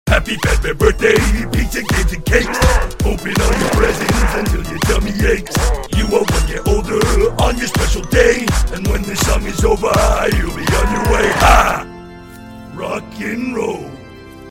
Monty's voice acting/singing